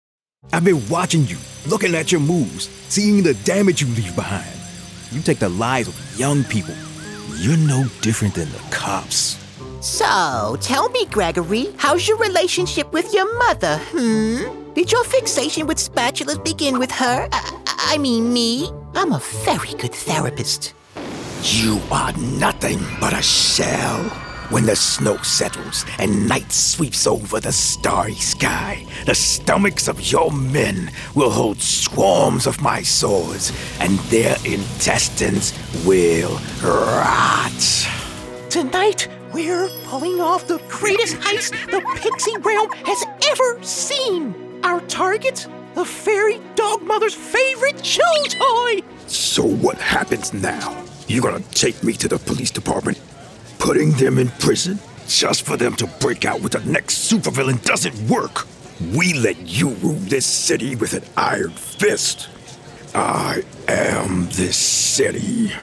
Animation
Meine Stimme ist hymnisch, geerdet und unverkennbar echt – eine Mischung aus Textur, Kraft und Seele, die das Publikum berührt und Ihre Botschaft hervorhebt.
Sennheiser 416, Neumann U87, TLM 103